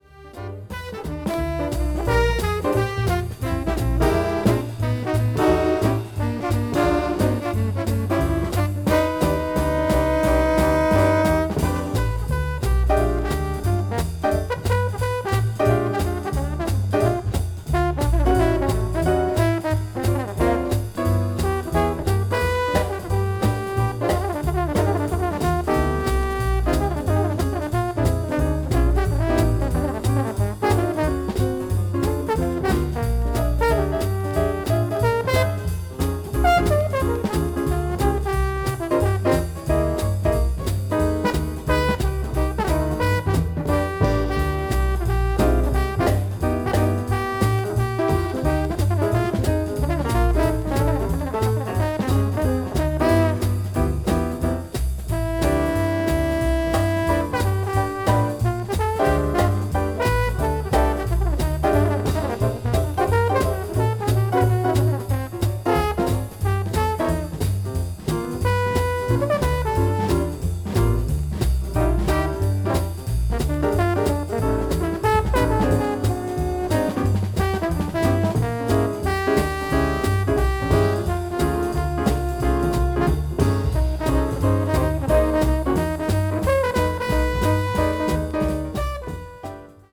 media : EX/EX-(B3 has two light click noises.)
hard bop   modern jazz   west coast jazz